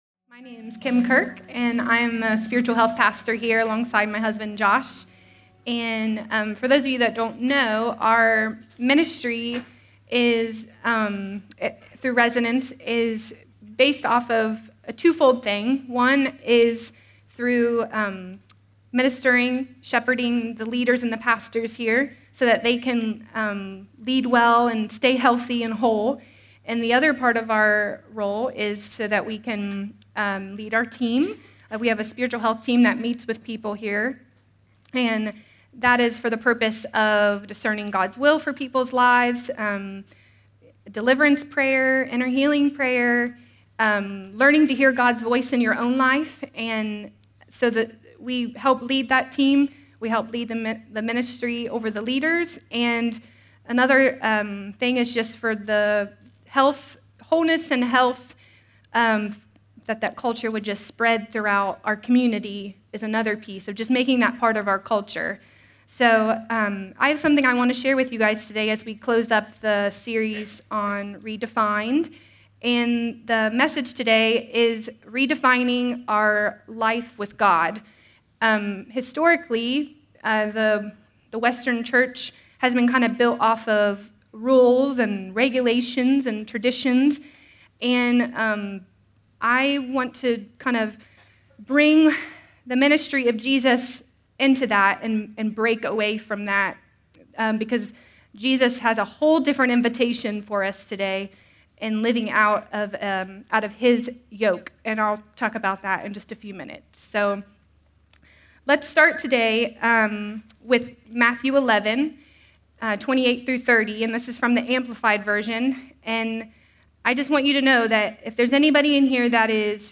Sermon
A sermon from the series “(RE)DEFINED.”…